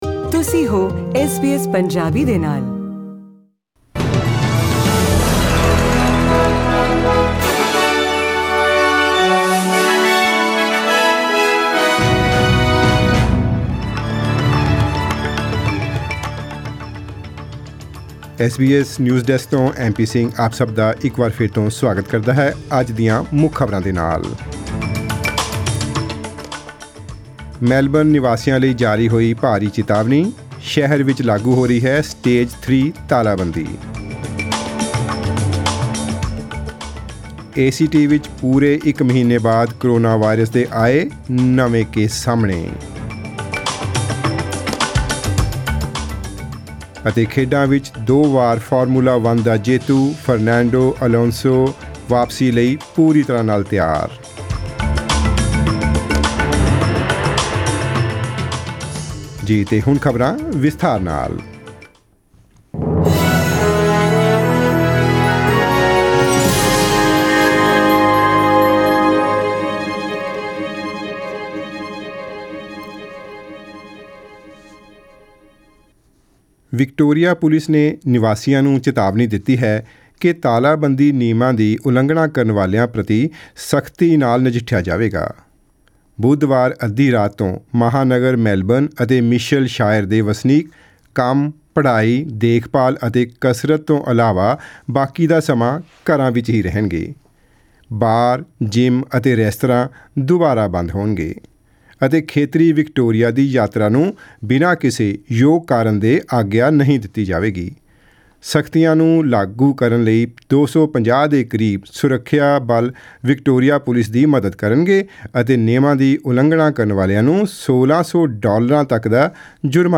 Presenting the major national and international news stories of today; sports, currency exchange details and the weather forecast for tomorrow.
Click on the player at the top of the page to listen to this news bulletin in Punjabi.